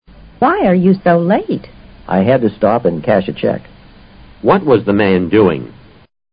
托福听力小对话【81】cash a cheek